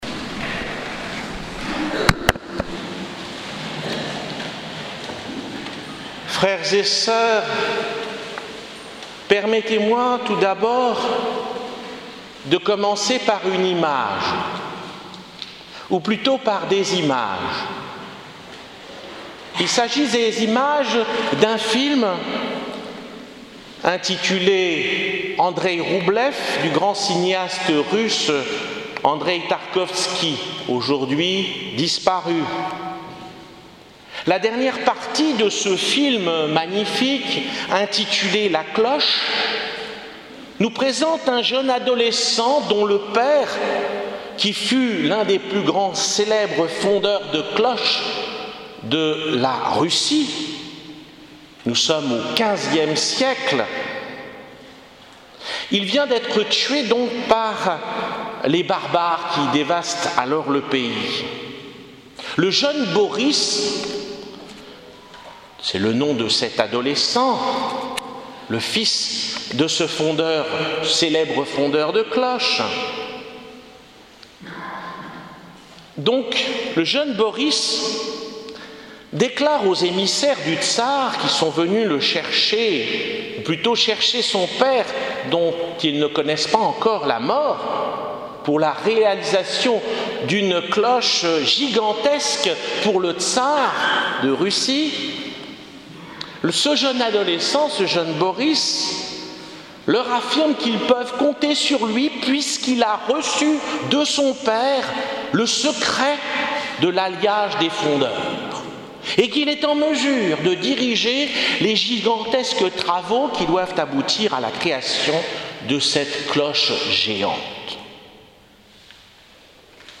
Homélie pour le Dimanche de Pentecôte 2017 | Les Amis du Broussey